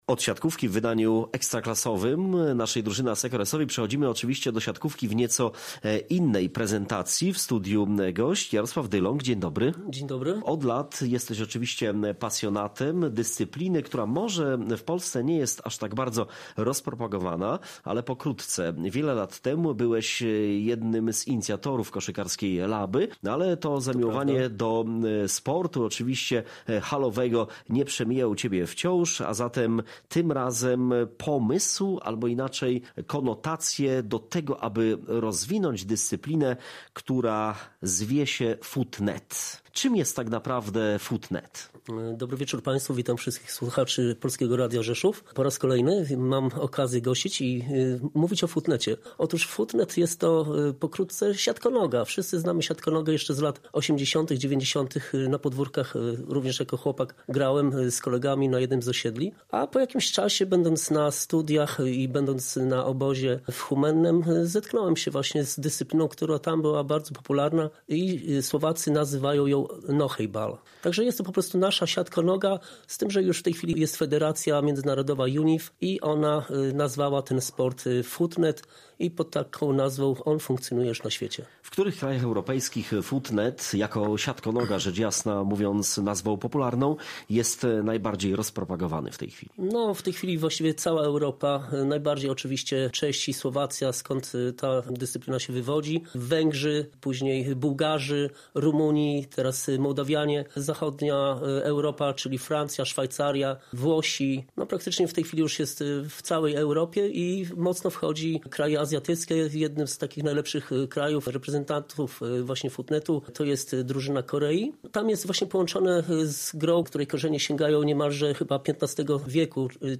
01.11-Futnet-rozmowa.mp3